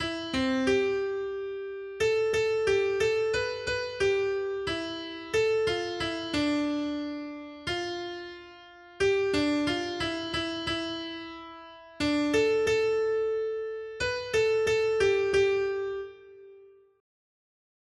responsoriální žalm